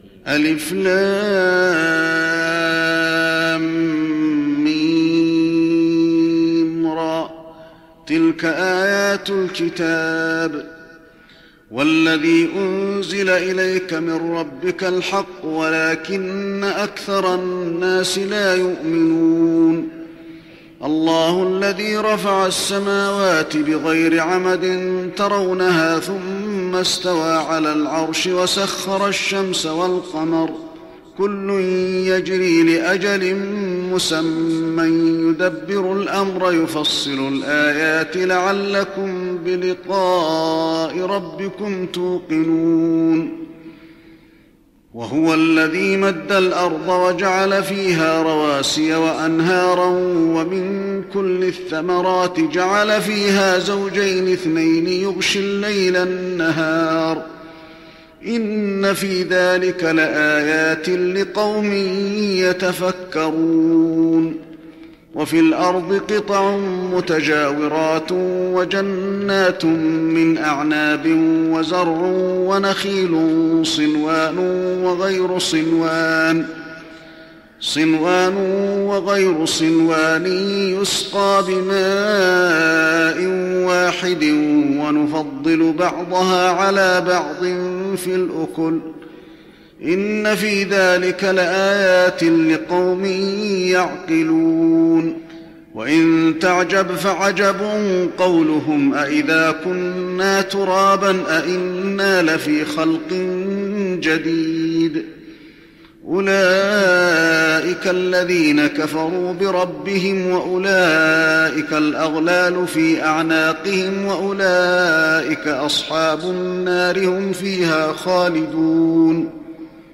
تراويح رمضان 1415هـ من سورتي الرعد و إبراهيم (1-34) Taraweeh Ramadan 1415H from Surah Ar-Ra'd and Ibrahim > تراويح الحرم النبوي عام 1415 🕌 > التراويح - تلاوات الحرمين